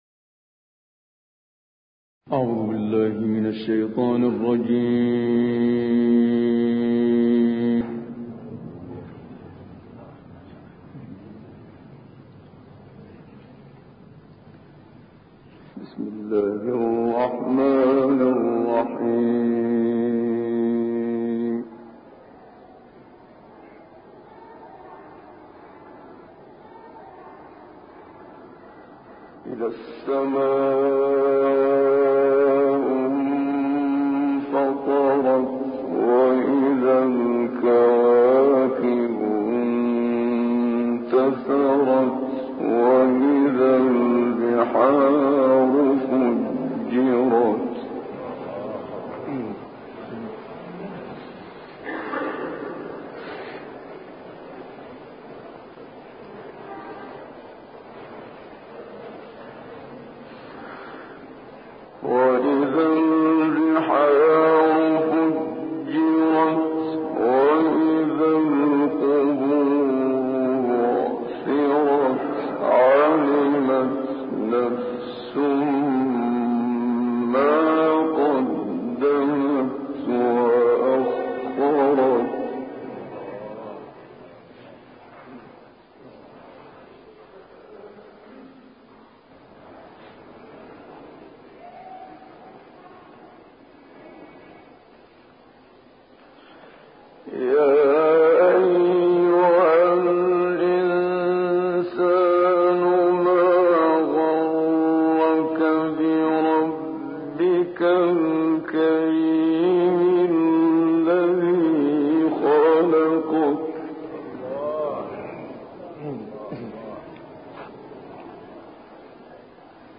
در کویت سال1966